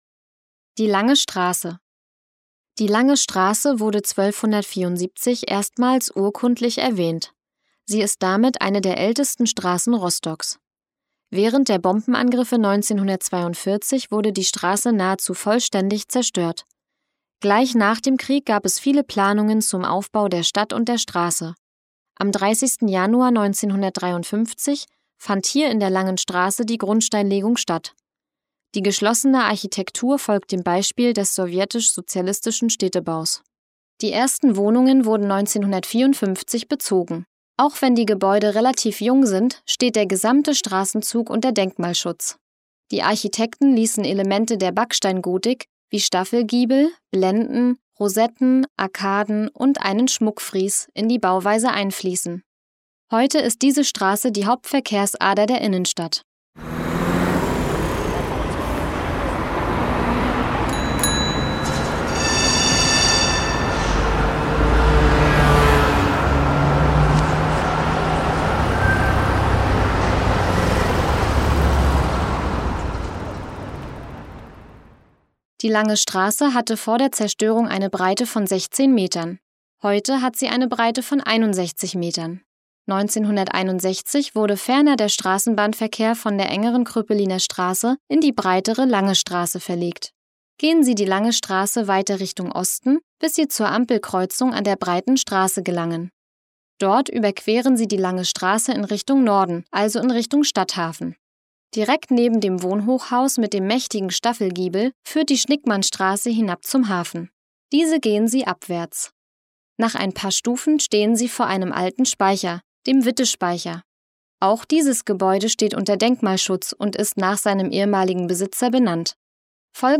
Audioguide Rostock - Station 7: Lange Straße
Aufnahmestudio: Tonstudio Rostock